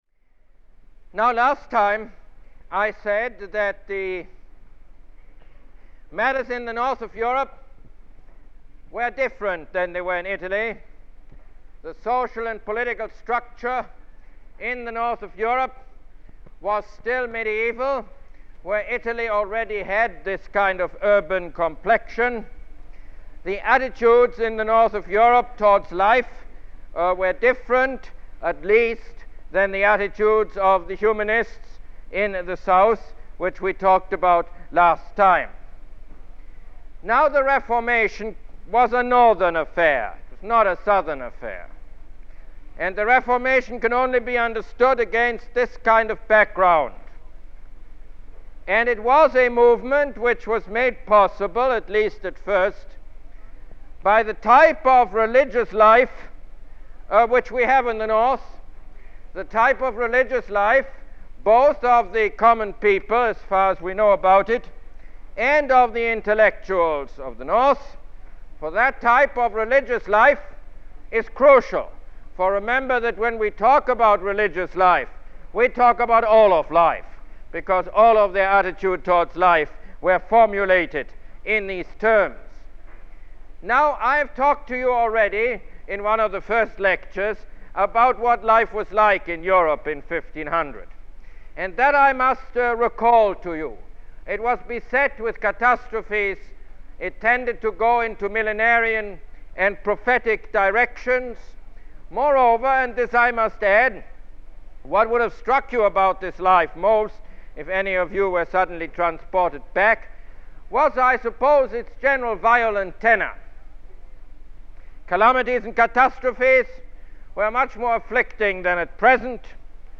Lecture #4 - Popular Culture and Humanism in Northern Europe